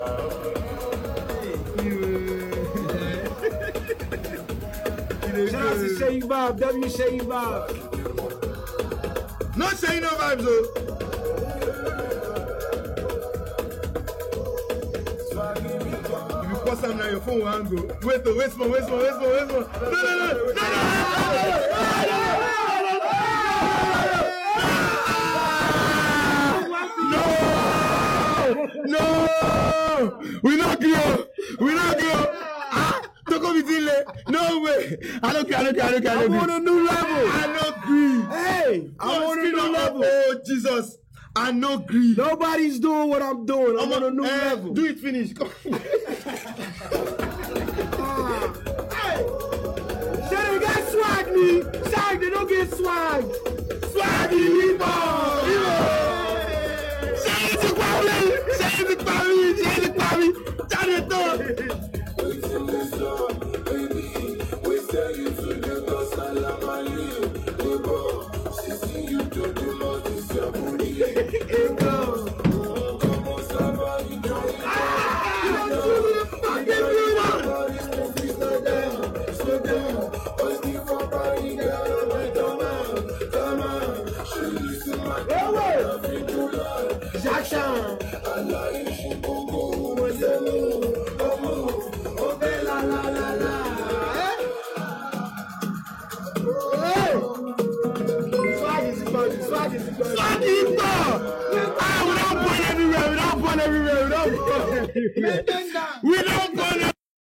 street-pop
His raw vocal texture